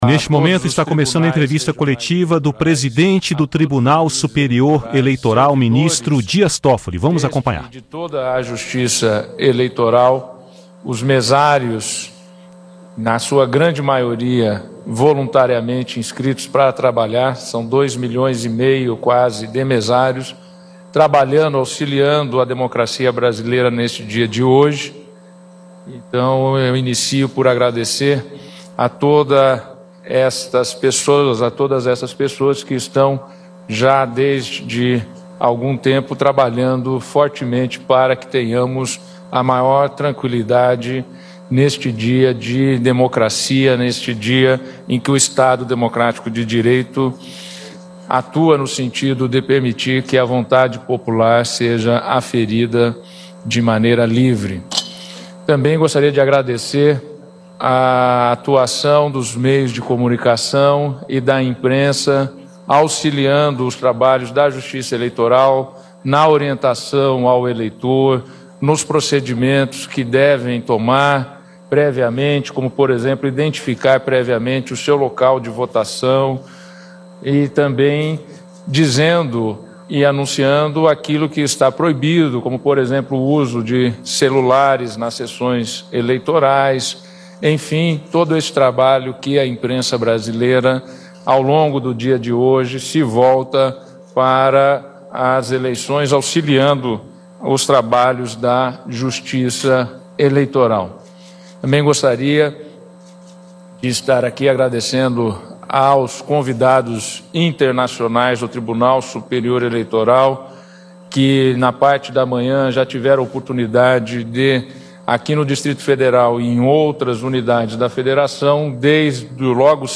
1ª Coletiva do TSE: Eleições transcorrem dentro da normalidade